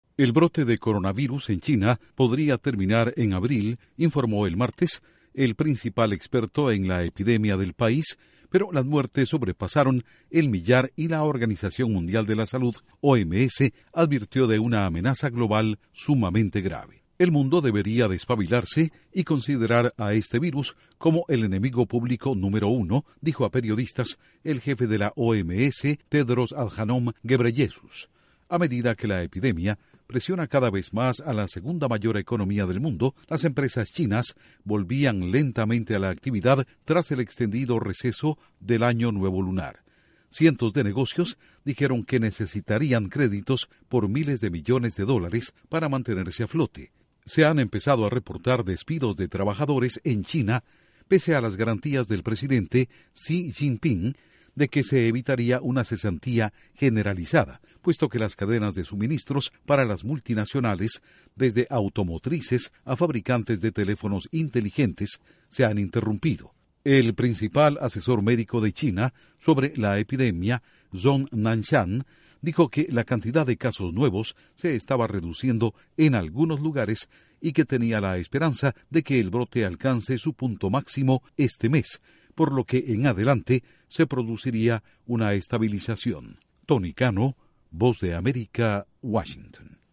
OMS dice que el coronavirus es el "enemigo público número uno" y que la epidemia podría terminar en abril. Informa desde la Voz de América en Washington